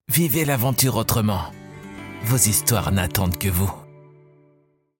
Male
Assured, Authoritative, Character, Confident, Cool, Corporate, Deep, Engaging, Natural, Reassuring, Smooth, Soft, Warm, Versatile, Young
Documentary.mp3
Microphone: se electronics x1s